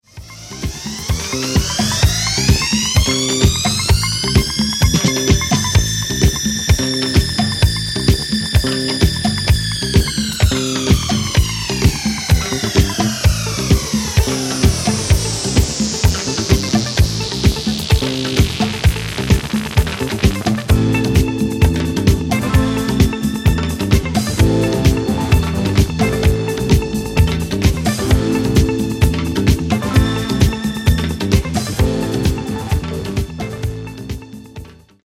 Genere:   Disco| Funky | Soul |